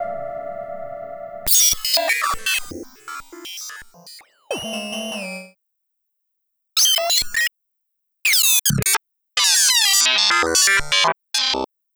Track 16 - Glitch 01.wav